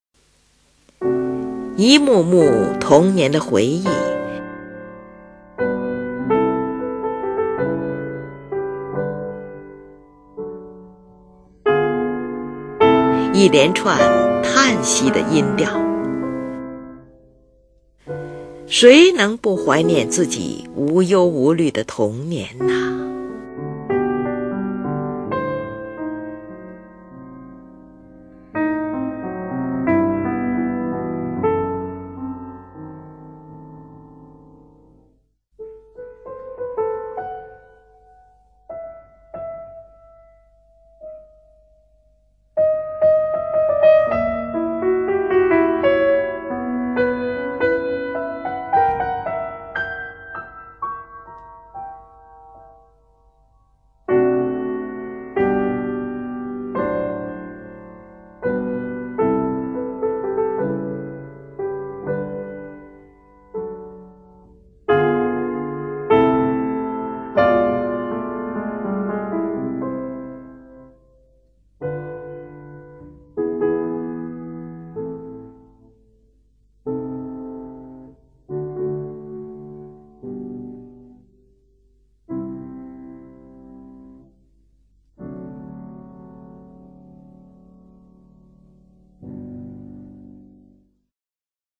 乐曲在末尾出现了一连串叹息的音调，使人感受到一种无名的惆怅……